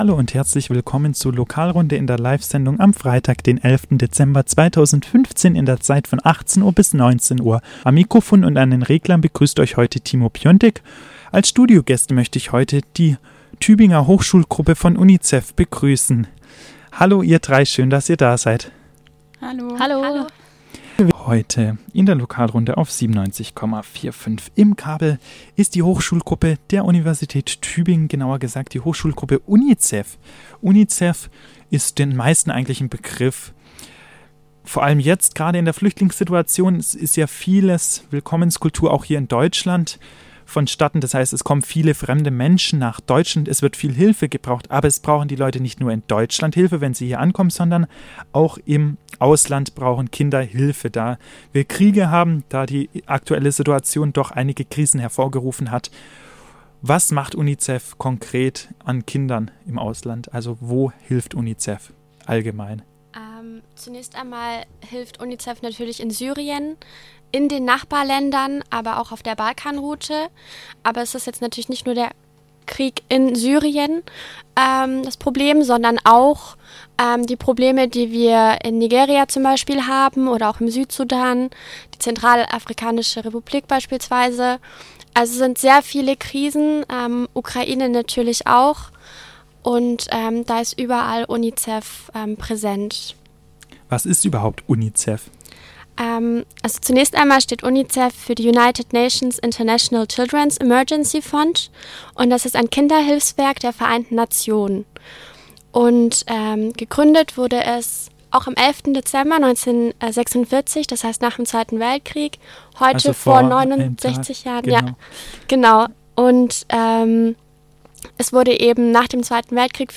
Im Studio: Die Tübinger Hochschulgruppe von UNICEF.